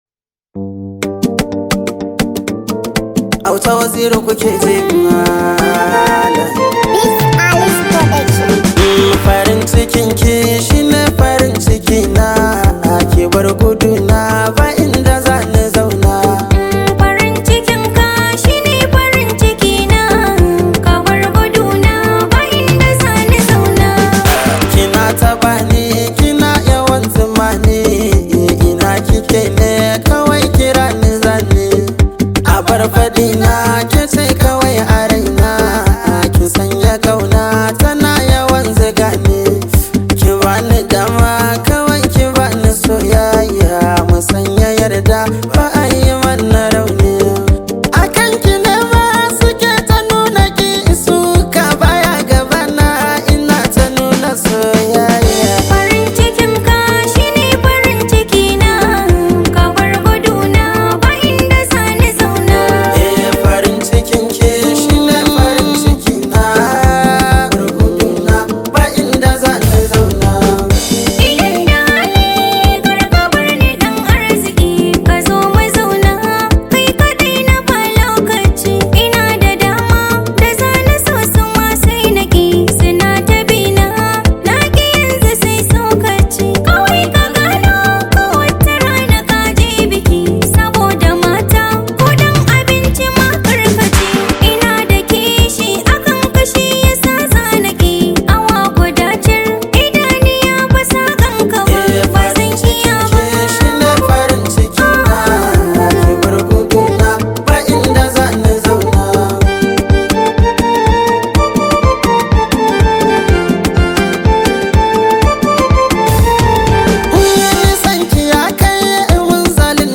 Hausa Musics